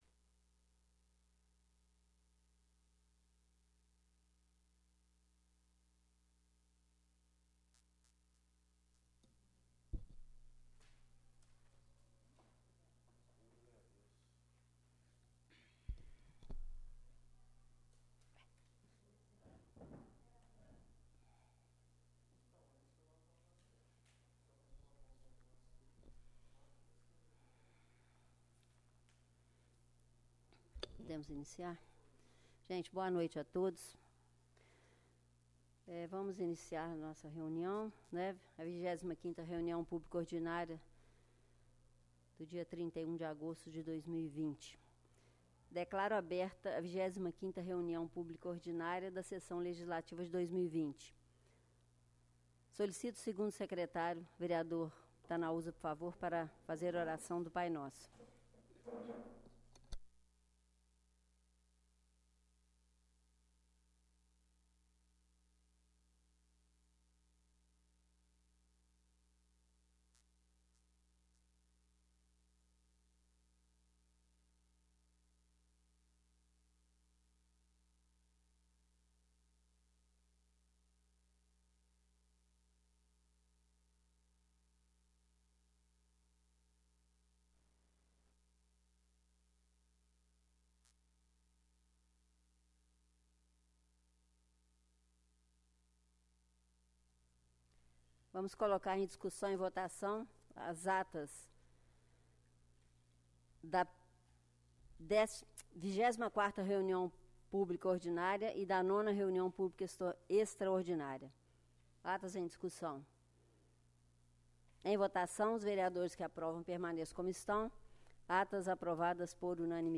25ª Reunião Ordinária